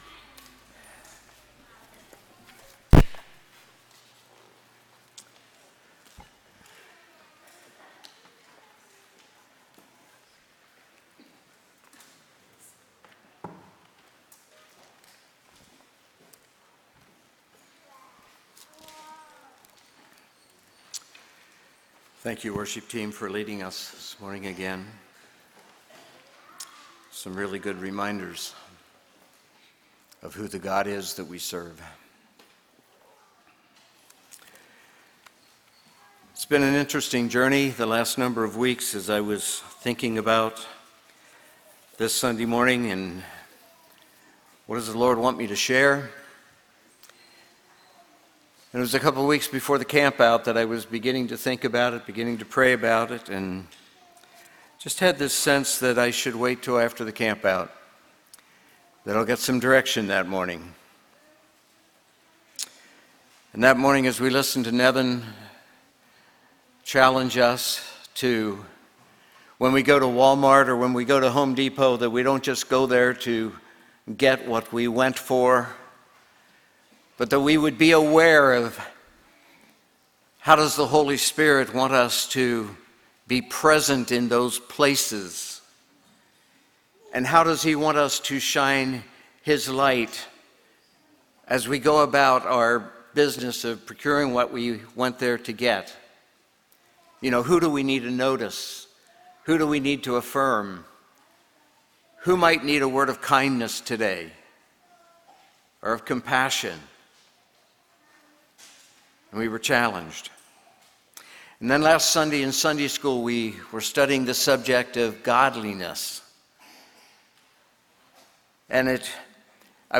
Sermon Archive | - New Covenant Mennonite Fellowship
From Series: "Sunday Morning - 10:30"